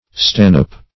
Stanhope \Stan"hope\ (st[a^]n"h[=o]p; colloq. st[a^]n"[u^]p), n.